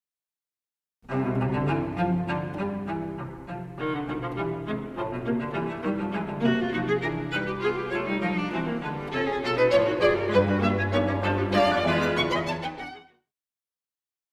this multi key-centred technique is known as polytonality